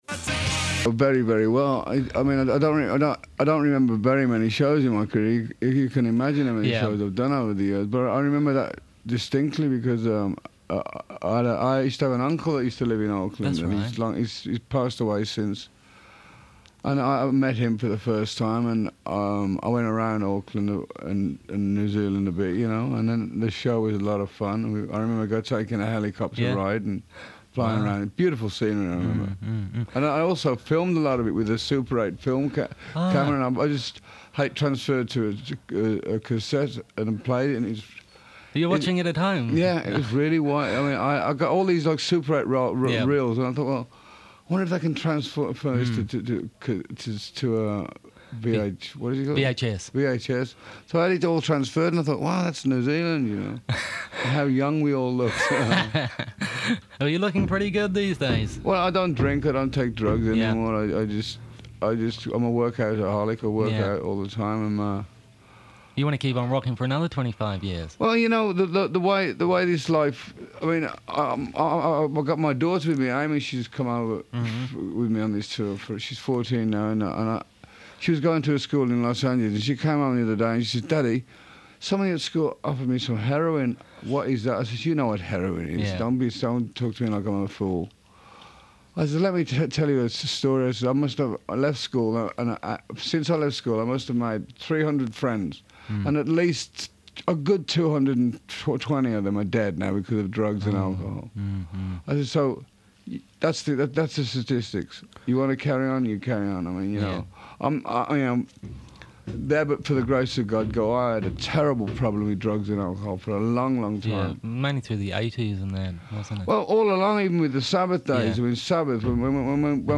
Before his sold-out Auckland show on 12 February 1998, Ozzy Osbourne climbed up the stairs to be interviewed at the bFM Studios the day before. Please ignore the construction downstairs.
Despite the constant banging of construction downstairs from Studio 1, Osbourne speaks about the 90s looks like for him, a compilation album release, his familiaral connection to New Zealand, and him getting fired from Black Sabbath.